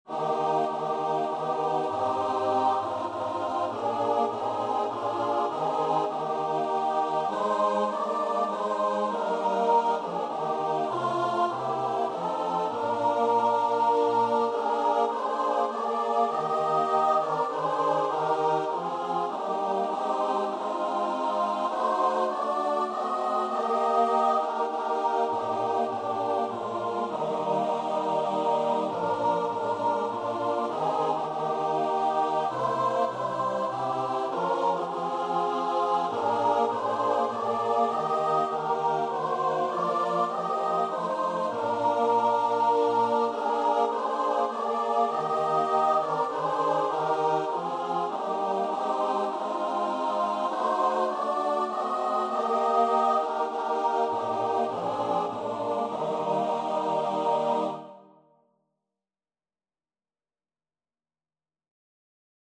3/4 (View more 3/4 Music)
Choir  (View more Easy Choir Music)
Classical (View more Classical Choir Music)